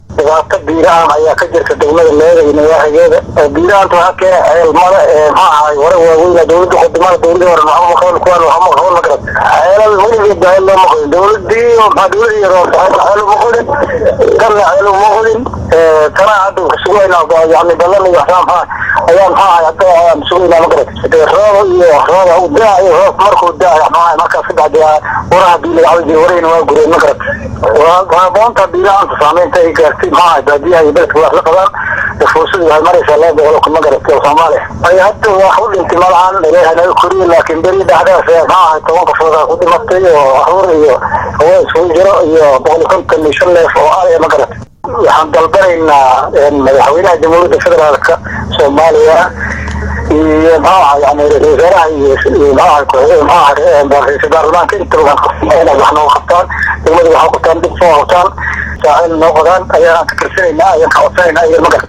Hadaladii gudoomiyahaa deegaankana waxaa ka mid ahaa..